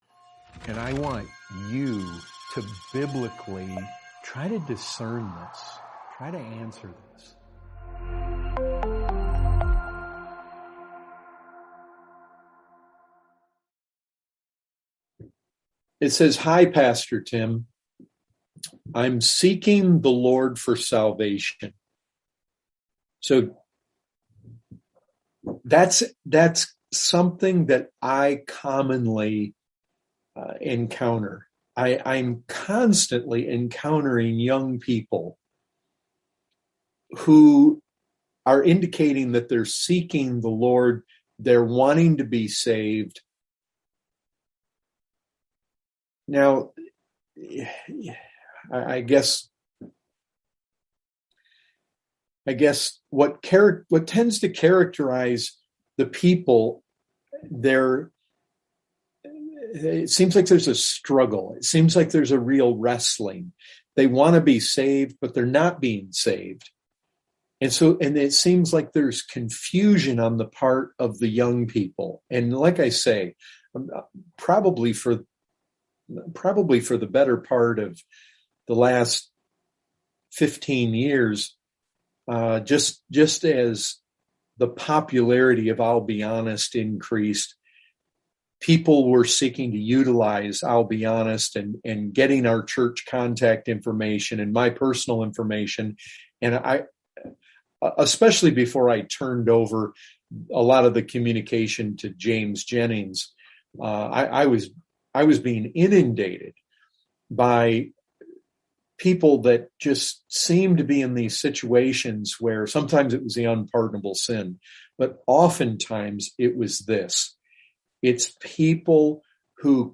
Questions & Answers Topic